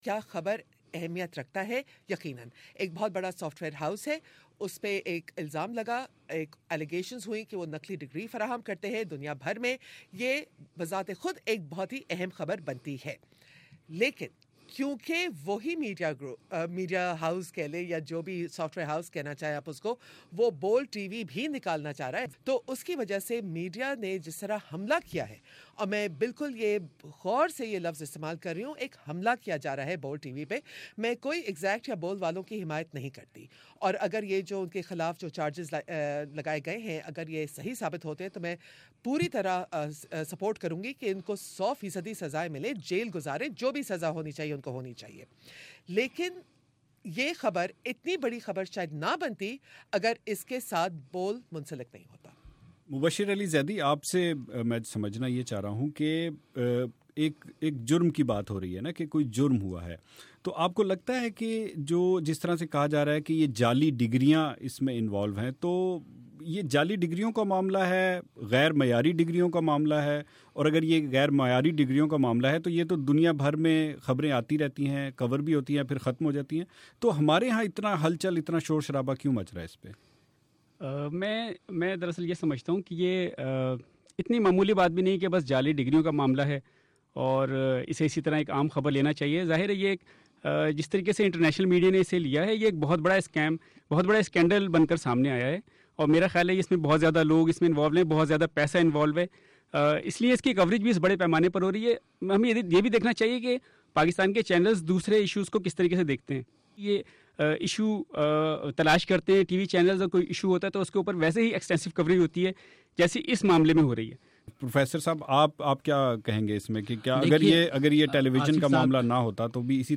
پاکستانی چینلز پر جاری مختلف میڈیا ہاؤسز کی جنگ کے بارے میں صحافت کی تین نمایاں شخصیات ے گفتگو۔